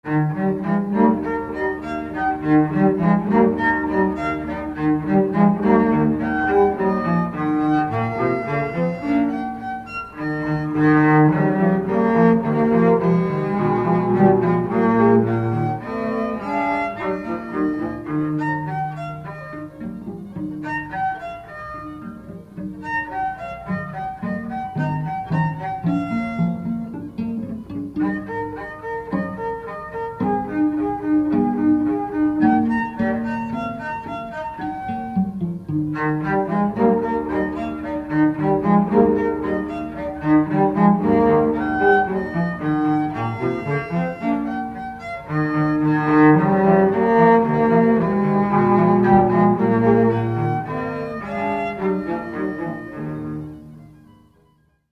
Pièces pour 3 violoncelles